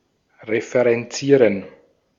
Ääntäminen
Synonyymit cite citation (ohjelmointi) pointer Ääntäminen US UK : IPA : /ˈɹɛf.ɹəns/ IPA : /ˈɹɛf.ɜː(ɹ).əns/ Haettu sana löytyi näillä lähdekielillä: englanti Käännös Konteksti Ääninäyte Substantiivit 1.